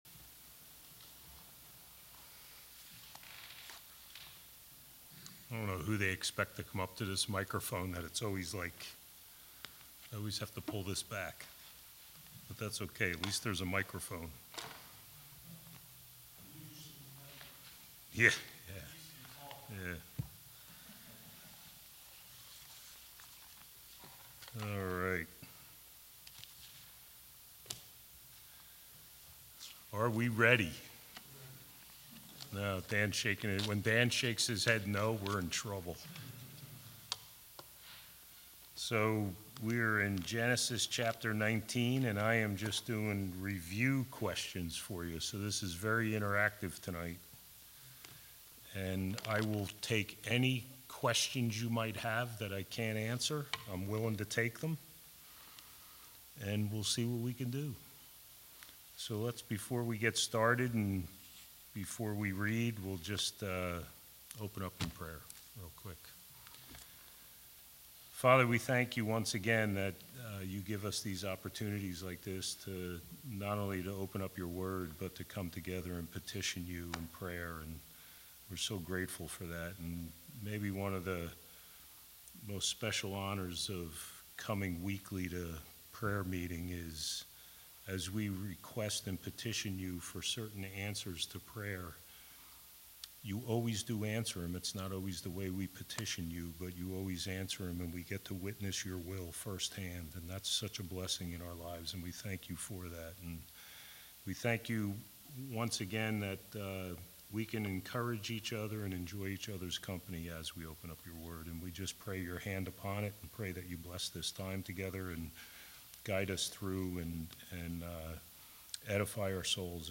All Sermons Genesis 19:1-14